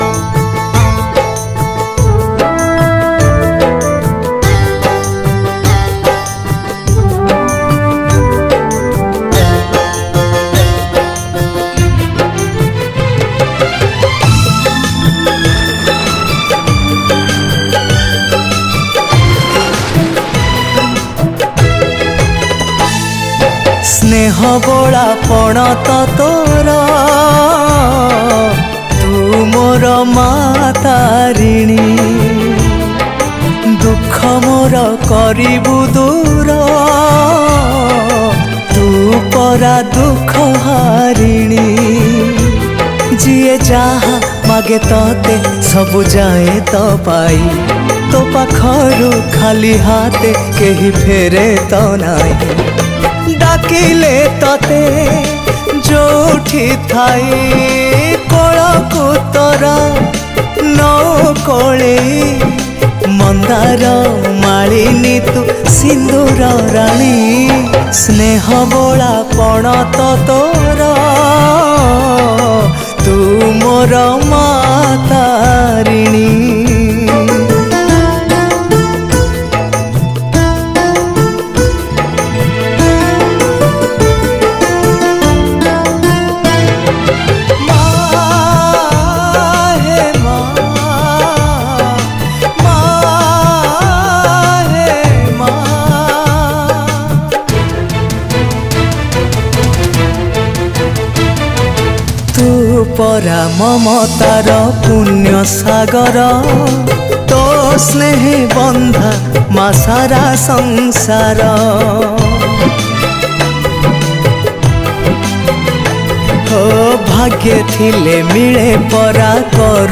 Odia Bhajan Songs